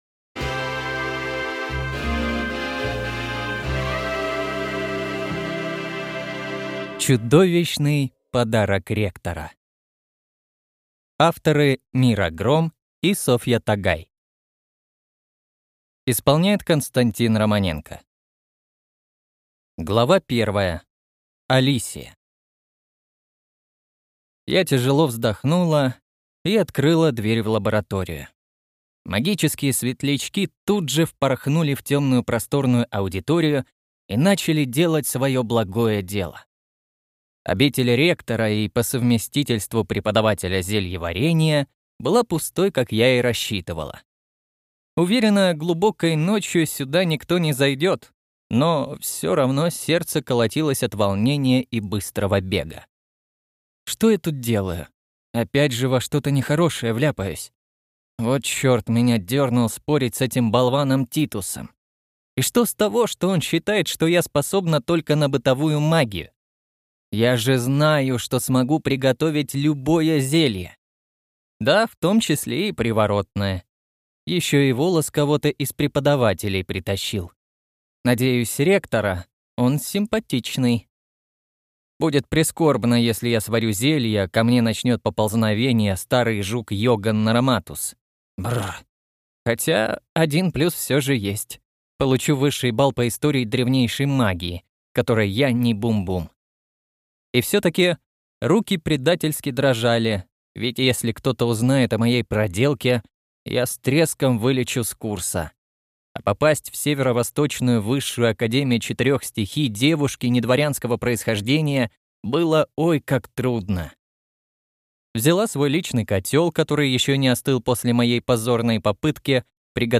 Аудиокнига Чудовищный подарок ректора | Библиотека аудиокниг